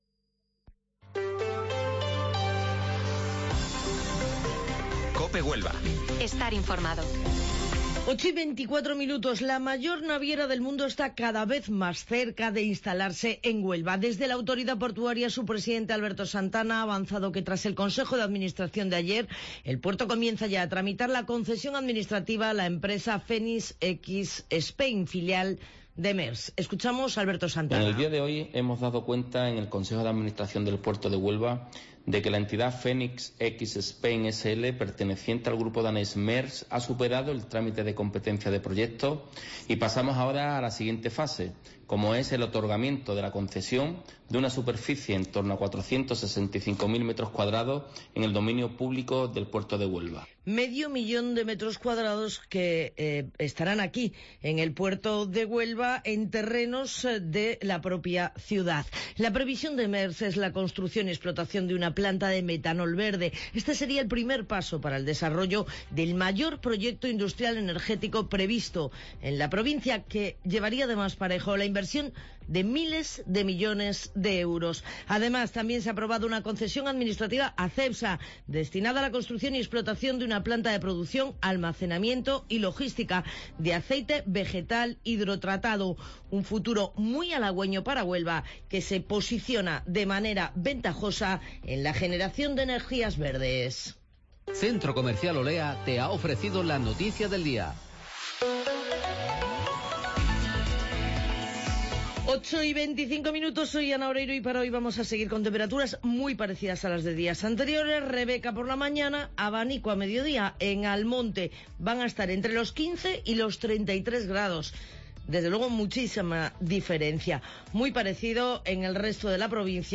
Informativo Matinal Herrera en COPE 27 de septiembre